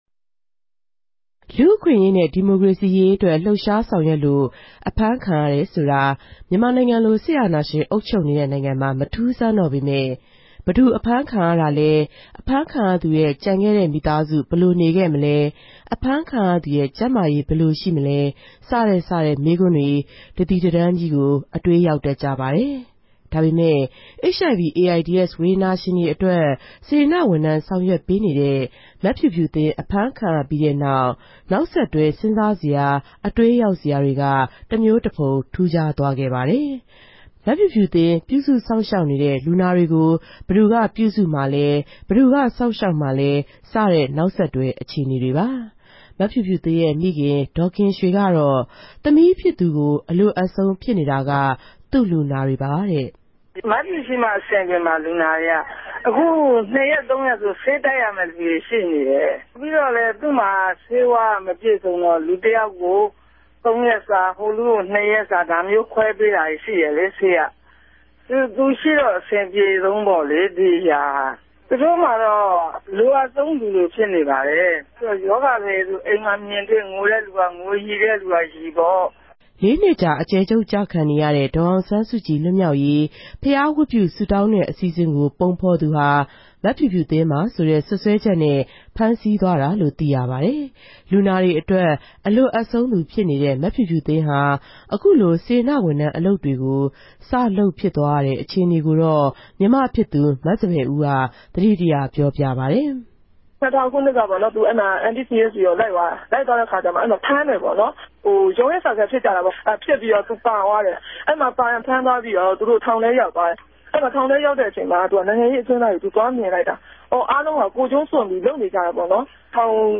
ကိုယ်တိုင် တက်ရောက် သတင်းယူ္ဘပီး စီစဉ်တင်ဆက်ထားပၝတယ်။